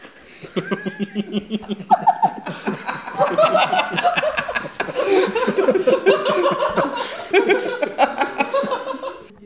ajout des sons enregistrés à l'afk ...
rire-foule_01.wav